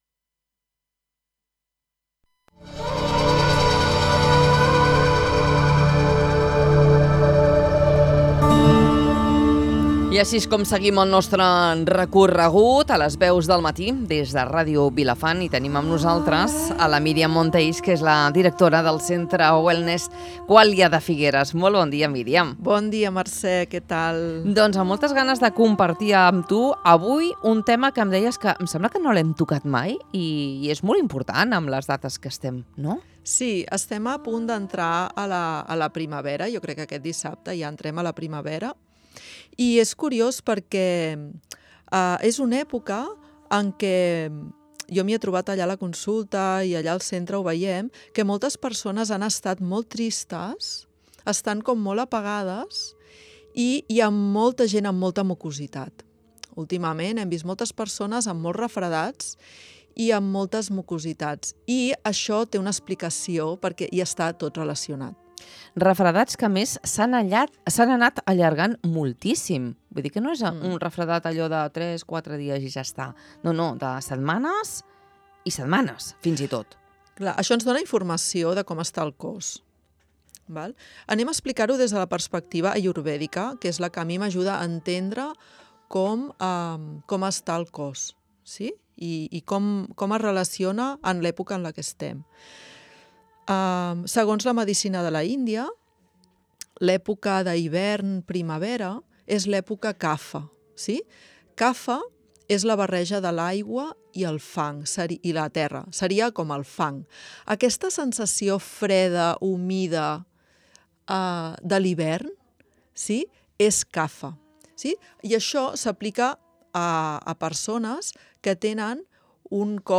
Una conversa per entendre millor per què el cos i la ment reaccionen d’aquesta manera a la primavera i com podem escoltar millor el nostre organisme en aquests períodes de canvi. https